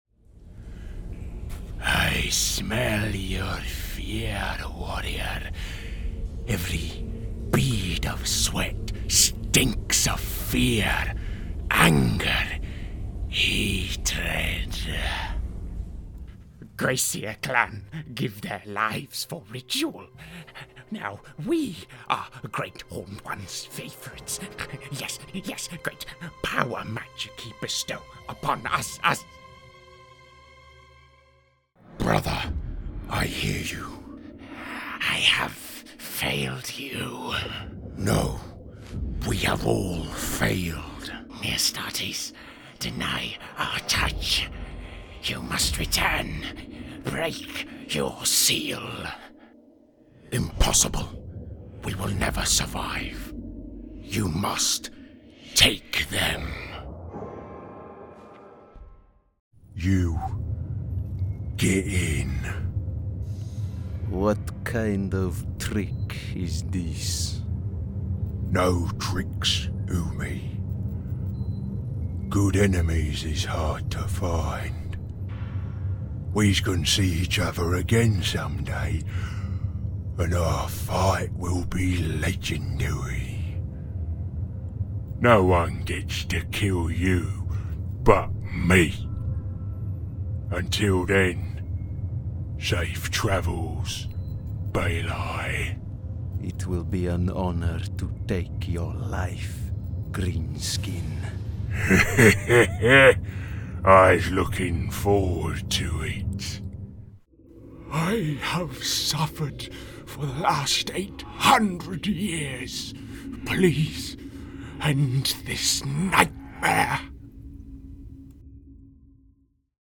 Creatures
Monster Showreel
Male
Northern
Confident
Authoritative